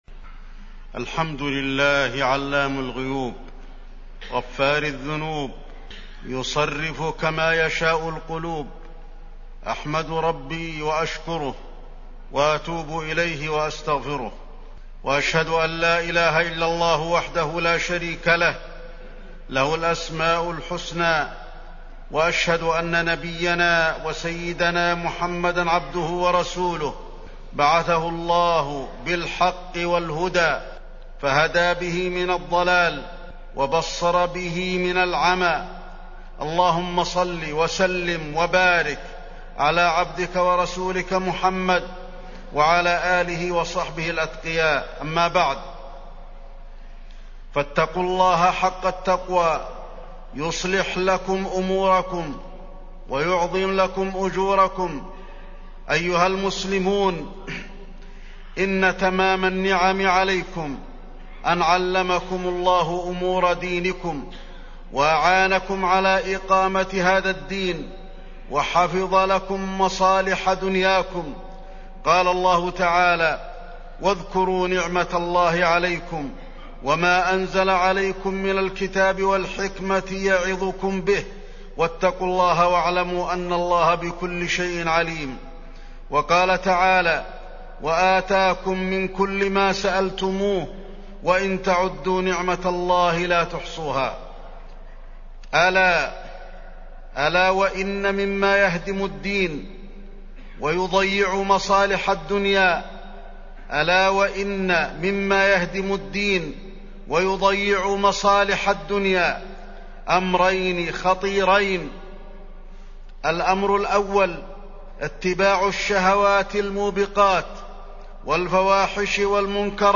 تاريخ النشر ٢٤ ربيع الثاني ١٤٢٨ هـ المكان: المسجد النبوي الشيخ: فضيلة الشيخ د. علي بن عبدالرحمن الحذيفي فضيلة الشيخ د. علي بن عبدالرحمن الحذيفي اتباع الشهوات